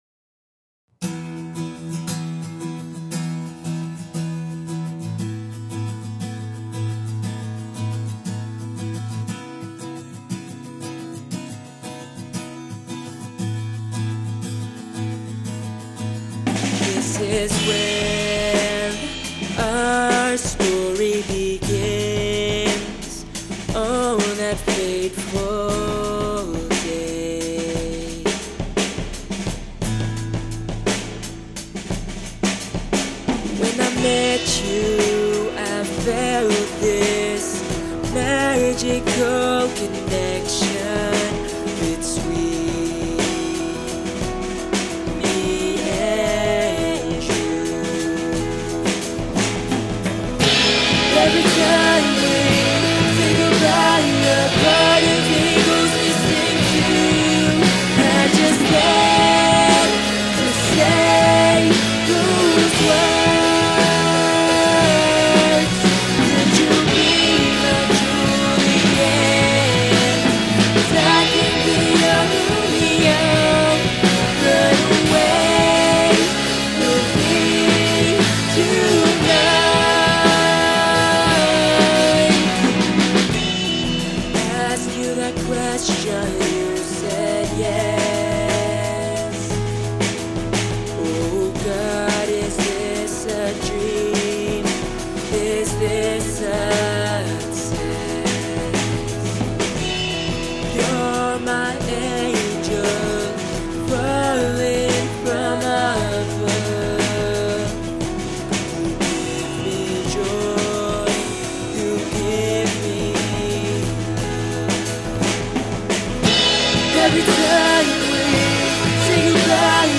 It's sorta pop/rock, and recently, I decided to have it recorded again.
Note that my gear isn't all that great, and my knowledge is still...
I have one ART M-One USB mic that I used for all the audio recordings of acoustic and electric guitar, vocals, and drums. The other instruments are software instruments within Logic.